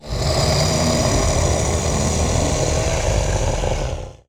Dragon_Growl_01.wav